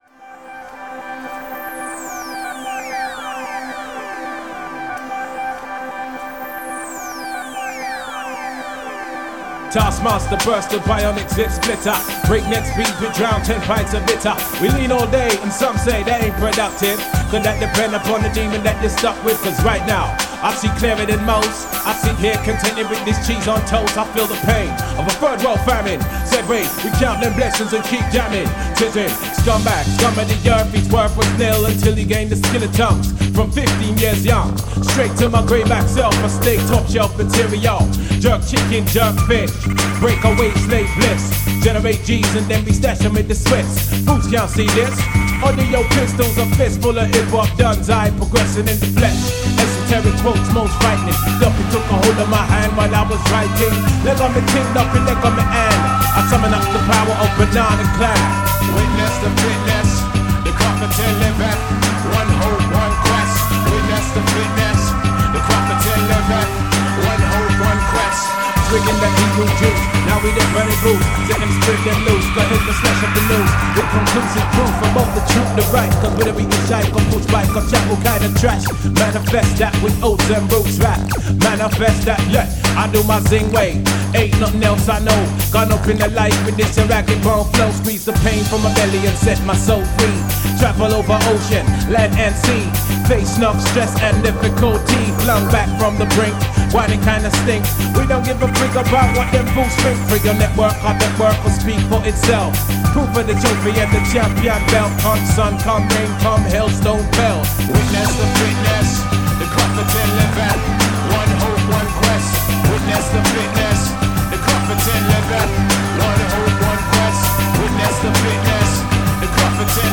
Big Beats and Rap Manuvas